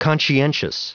Prononciation audio / Fichier audio de CONSCIENTIOUS en anglais
Prononciation du mot : conscientious
conscientious.wav